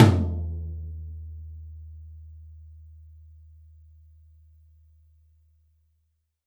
L_TOM.WAV